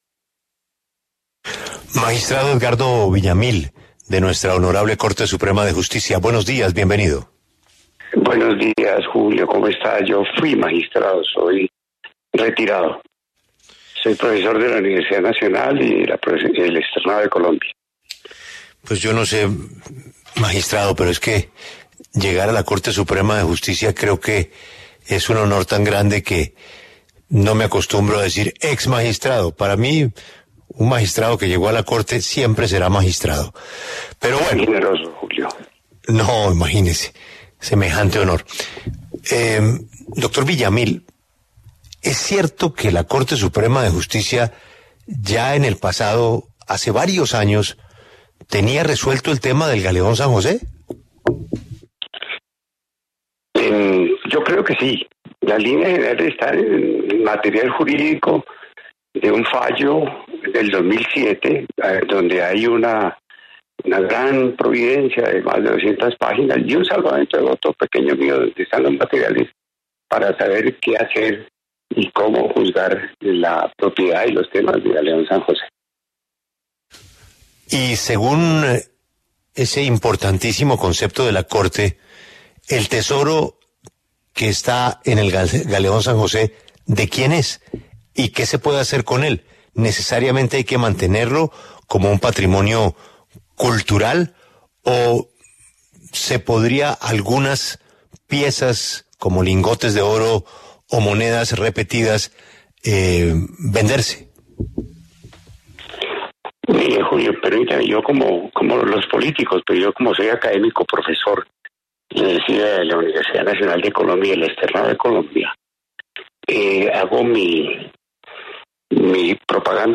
En diálogo con La W, el exmagistrado y profesor Edgardo Villamil, se refirió al Galeón San José y lo que plantea la Corte Suprema de Justicia sobre los hallazgos.